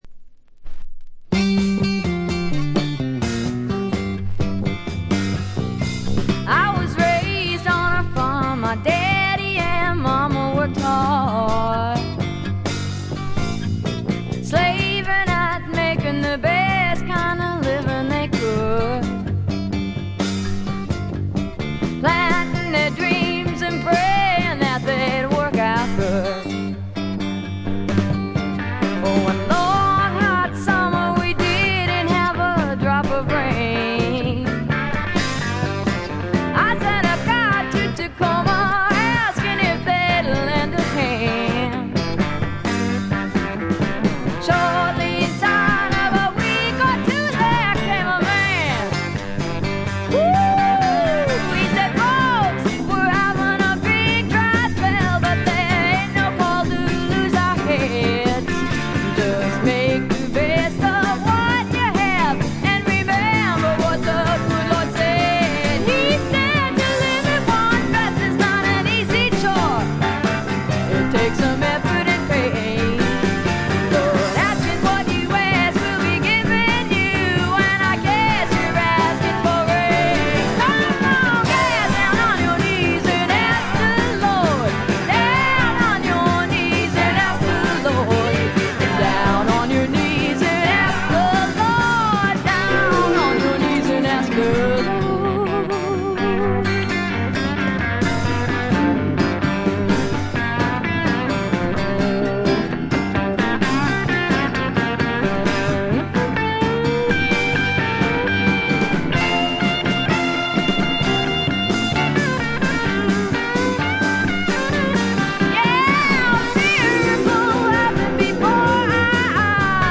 これ以外はところどころでチリプチ。
試聴曲は現品からの取り込み音源です。